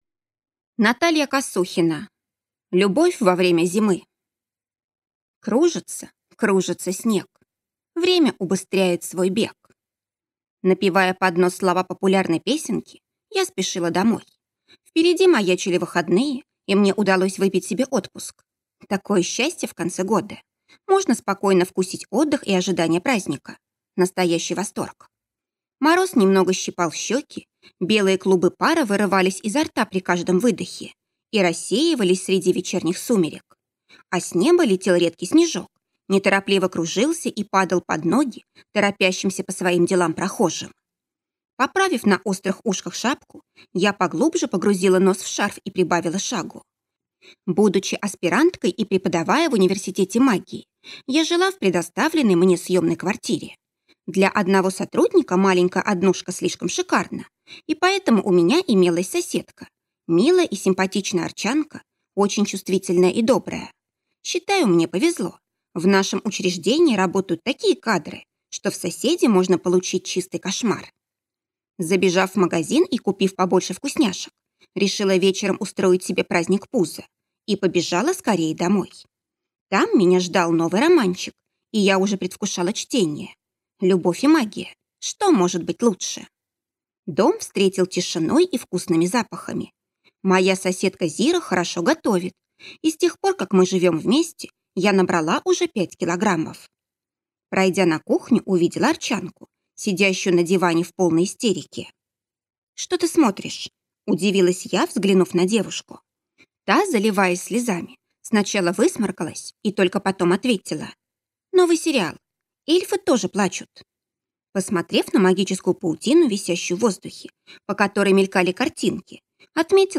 Аудиокнига Любовь во время зимы | Библиотека аудиокниг